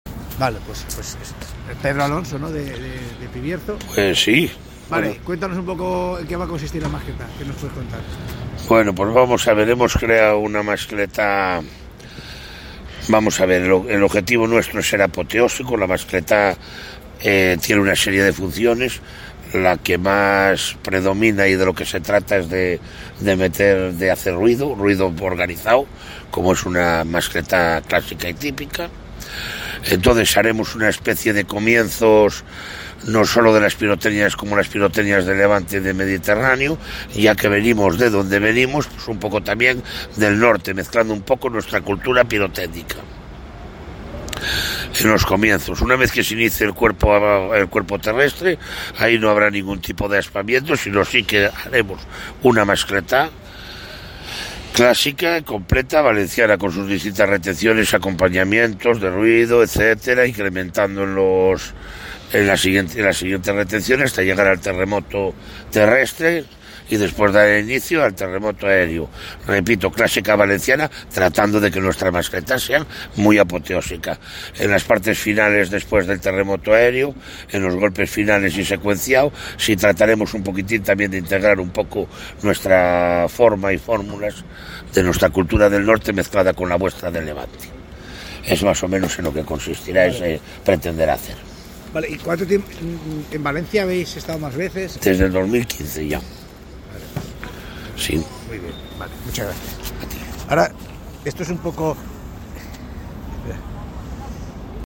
Hoy ha disparardo en la Plaza del Ajuntament la Pirotecnia Pibierzo, de Villaverde de la Abadía (León), una empresa que lleva participando en la plaza desde el 2015. La de hoy ha sido una mascletá de estilo “norteño”, con 107 kg de material pirotécnico que ha mezclado las diferentes tradiciones pirotécnicas de la zona norte del país con la tradición valenciana, con un cierre compuesto por diferentes golpes mecanizados rematando con un gran impacto de truenos totalmente hermético.